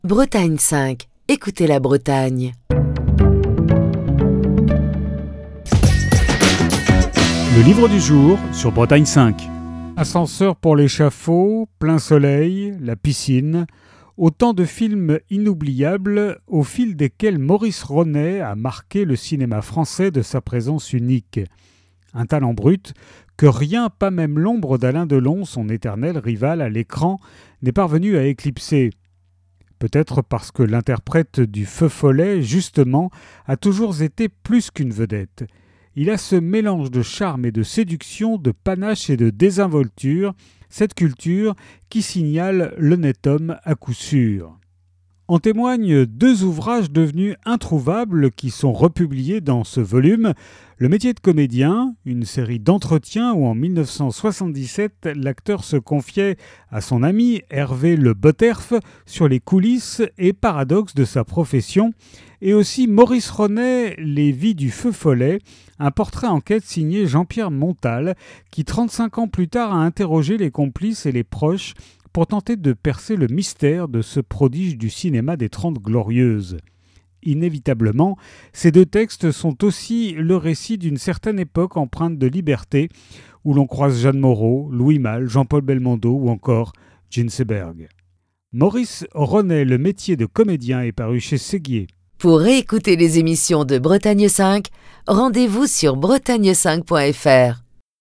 Chronique du 26 juin 2025.